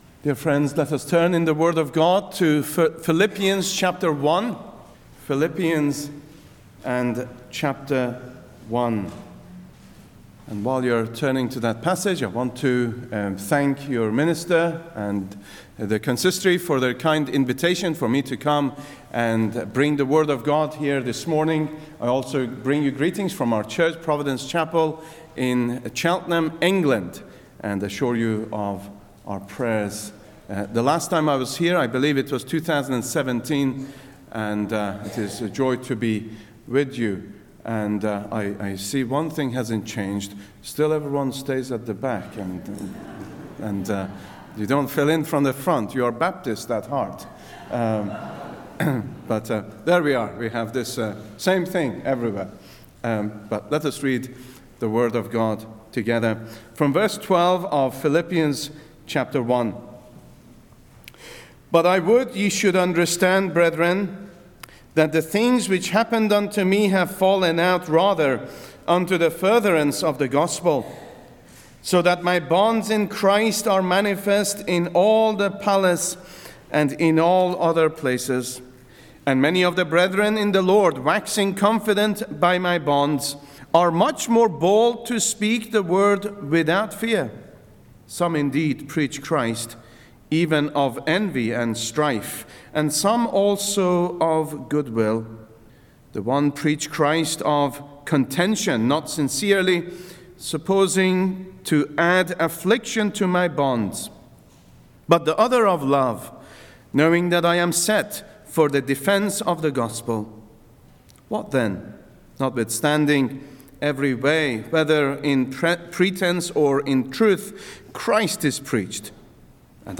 Heaven Series Various Sermons Book Philippians Watch Listen Save In Philippians 1:12–30, the apostle Paul describes his delight in serving Christ in the present and the greater joy that awaits in the future.